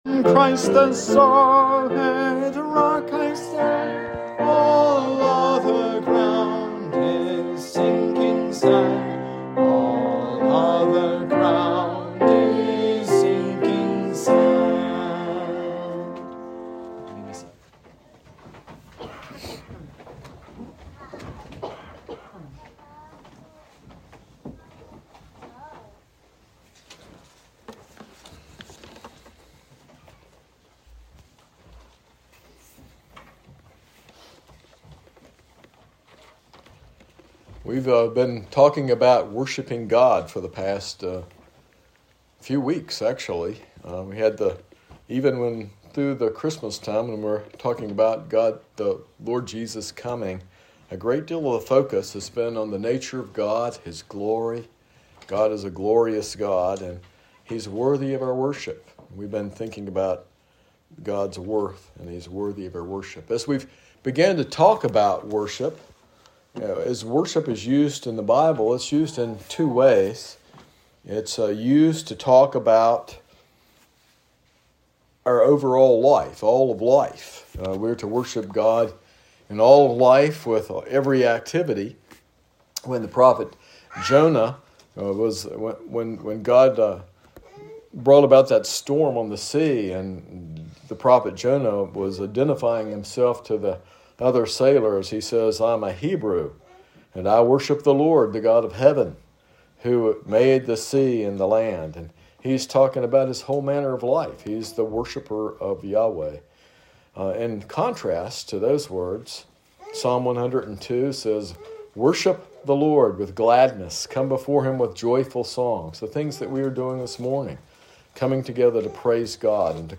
This sermon explores Psalm 113, highlighting God’s infinite glory and transcendence, yet also His profound humility and imminence in stooping down to redeem and uplift humanity.